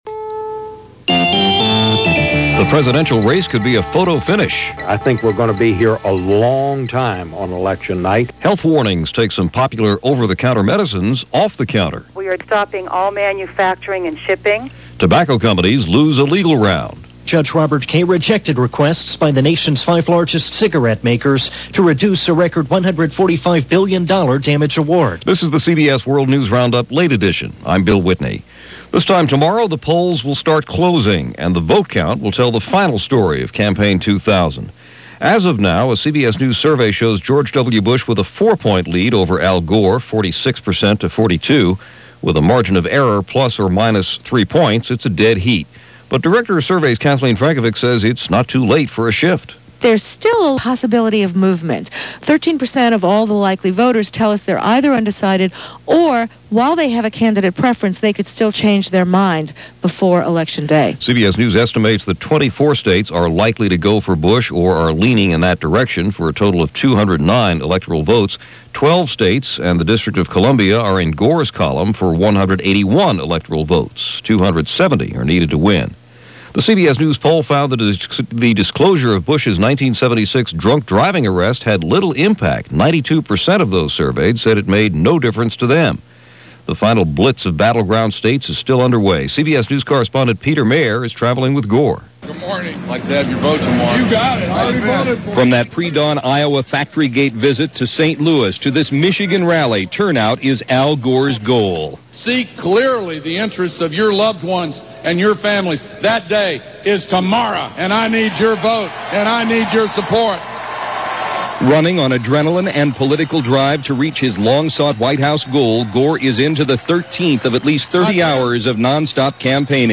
And that’s what was going on, this Election Eve – November 6, 2000 as reported on the CBS World News Roundup – Late Edition.